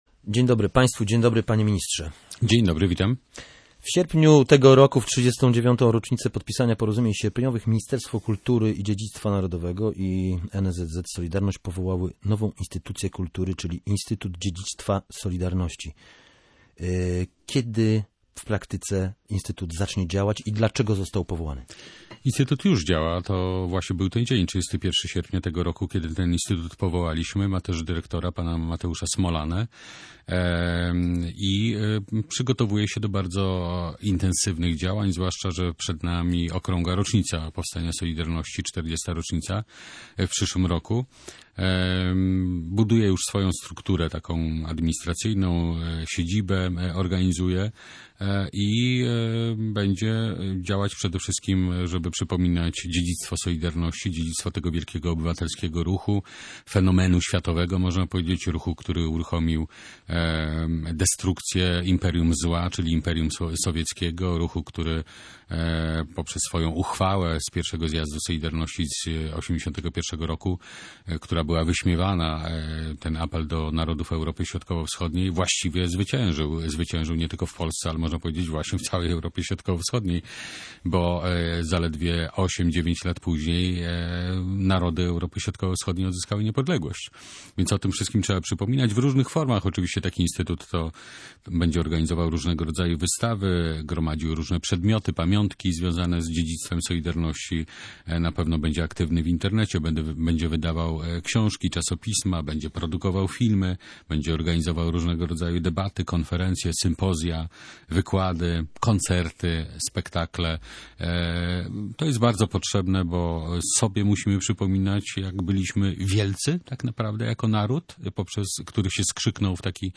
– Instytut już działa – mówił Jarosław Sellin.